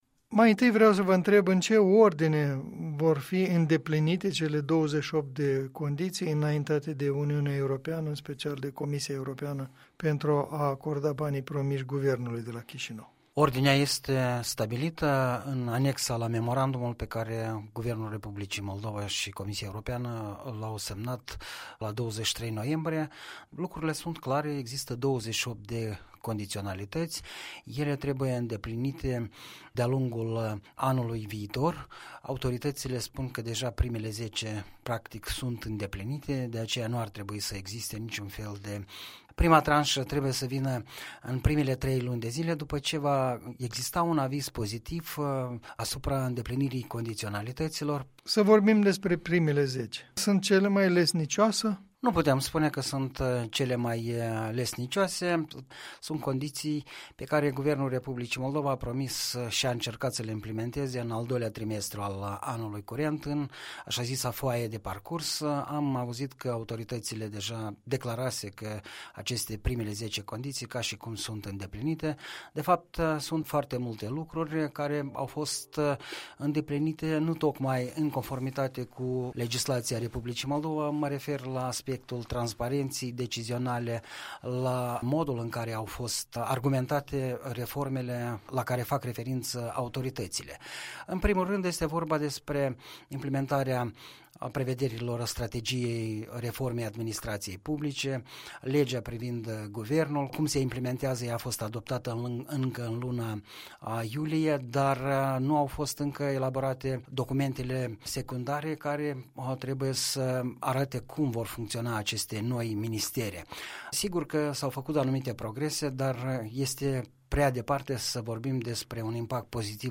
Punct de vedere săptămânal în dialog.